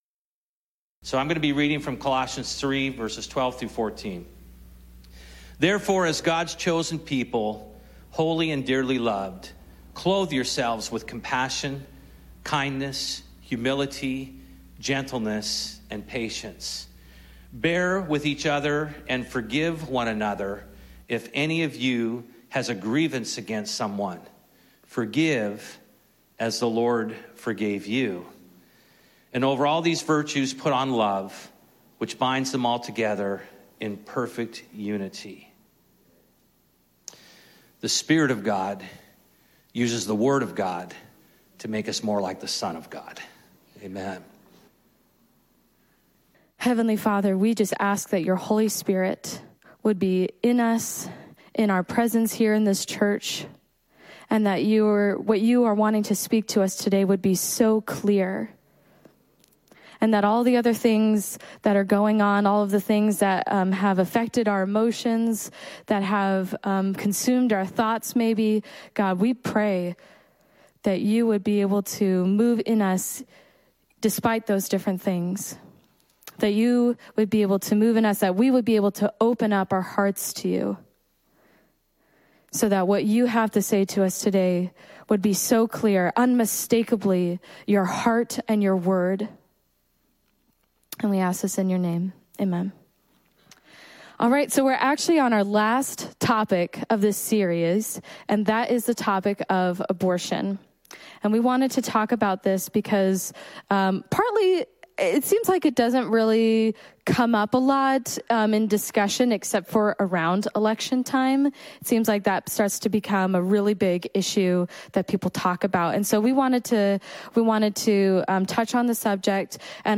A Discussion About Compassion and Conviction